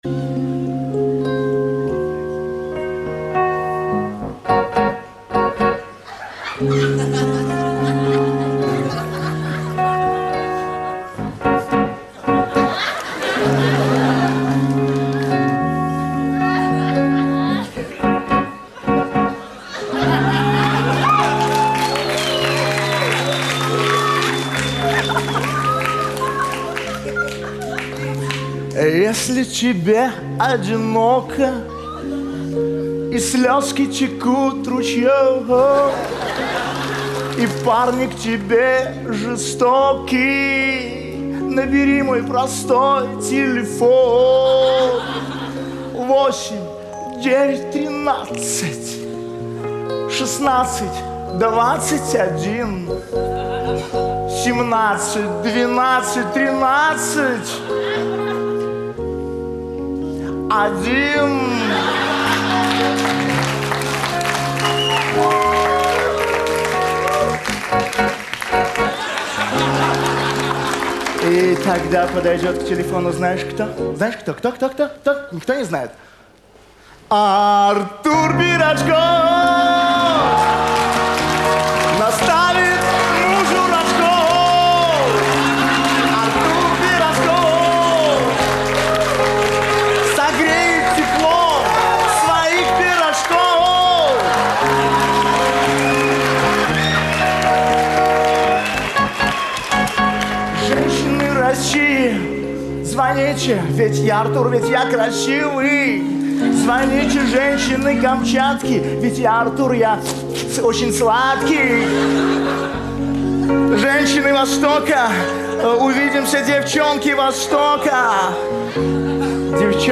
Жанр:Comedy/Club